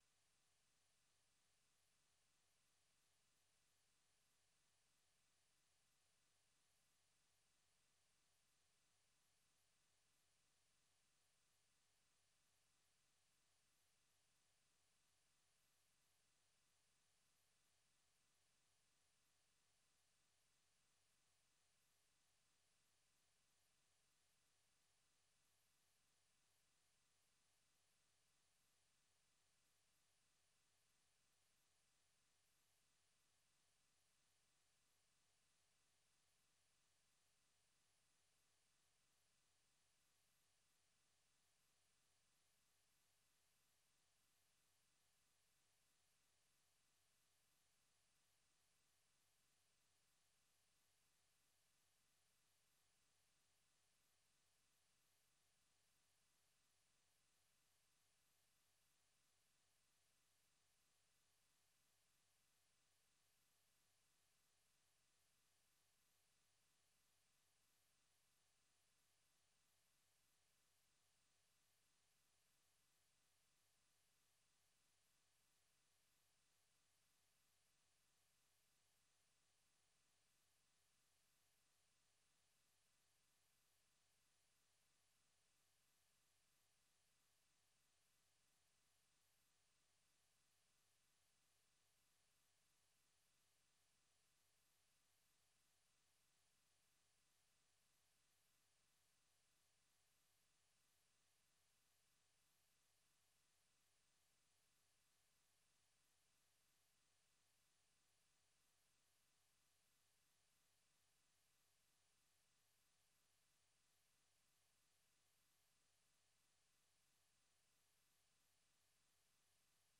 VOA Express ni matangazo mapya yenye mwendo wa kasi yakiangalia habari mpya za mchana na maelezo ya maswala yanayohusu vijana na wanawake. Matangazo haya yanafuatilia habari zilizojitokeza nyakati za mchana na ripoti za kina za habari ambazo hazisikiki sana katika matangazo mengineyo.